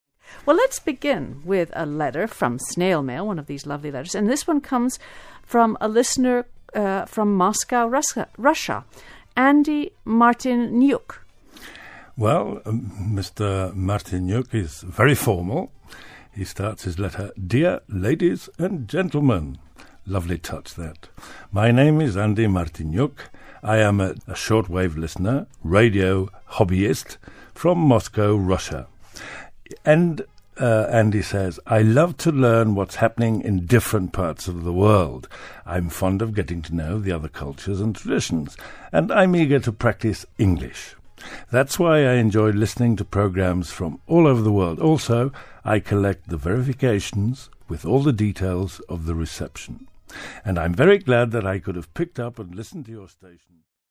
Here you will find two listening lessons based on the same snippets from an English Language Broadcast on the Spanish National Radio station.